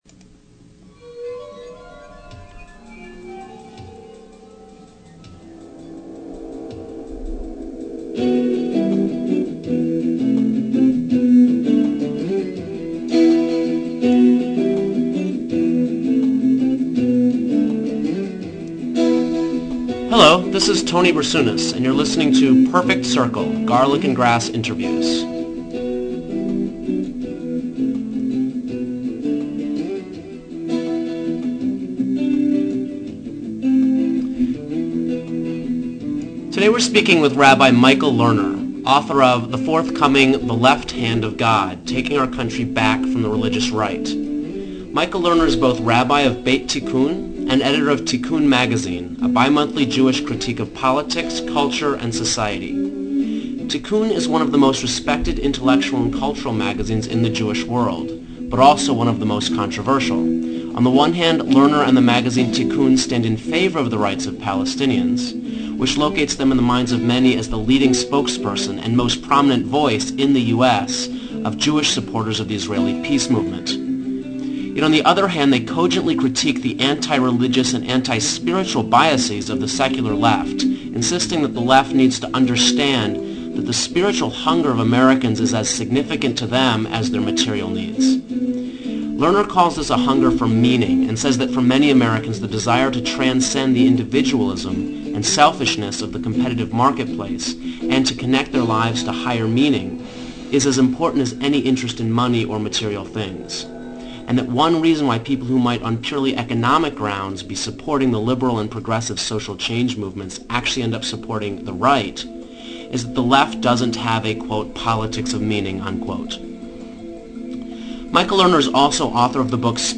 Rabbi Michael Lerner Interview
As part of our effort to explore a political perspective outside the mainstream corporate media's narrow purview, Garlic & Grass spoke with Lerner by phone.